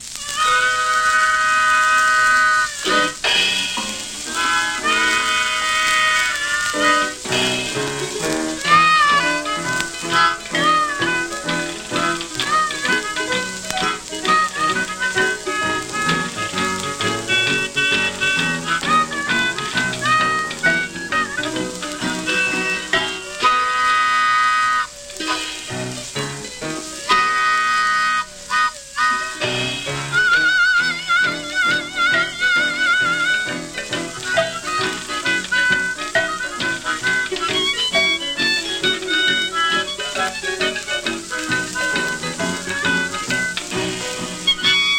Blues, Country Blues, Jug Band, Jazz　USA　12inchレコード　33rpm　Mono